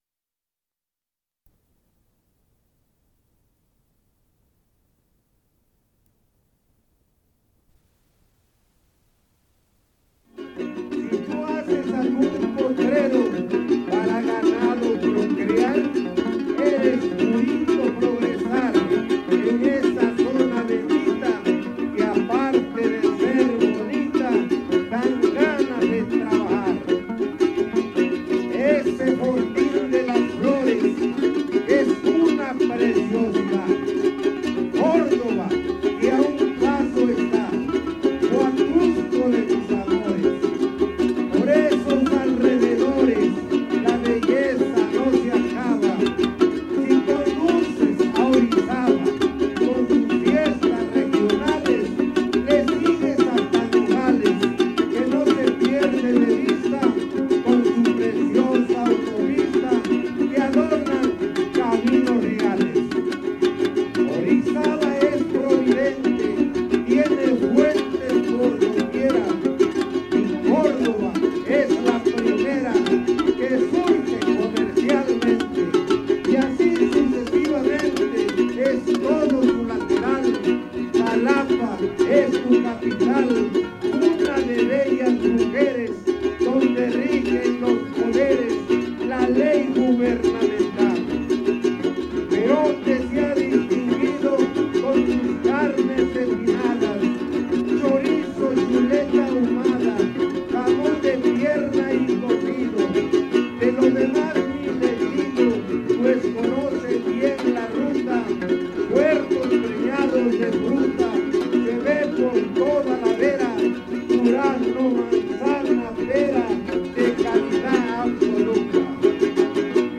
Fandango